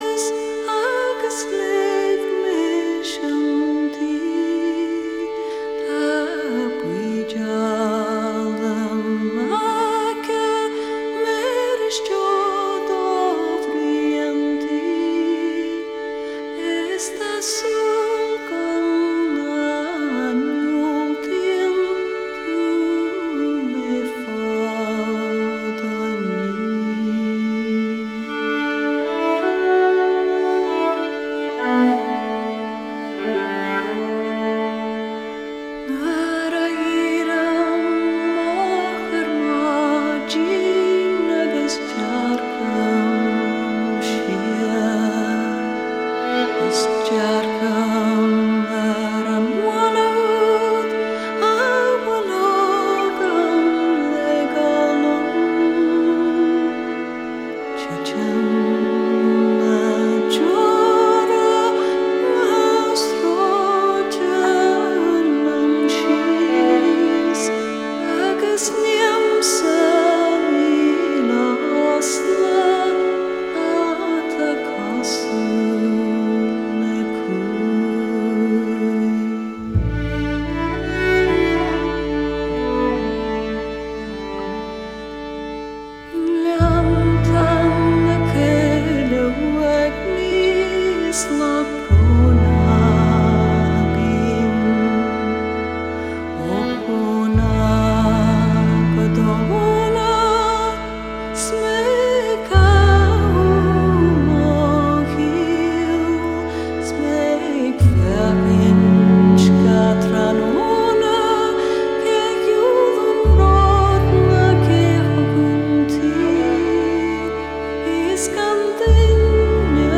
Электронная музыка
Фолк Рок